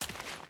Dirt Walk 1.wav